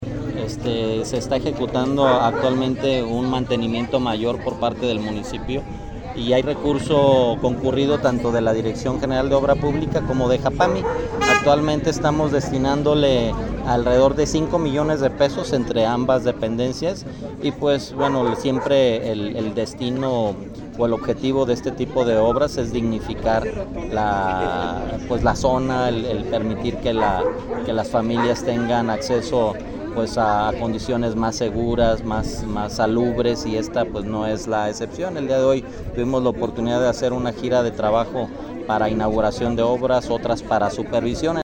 Rodolfo Gómez Cervantes, presidente interino